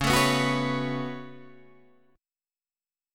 C#7sus4#5 Chord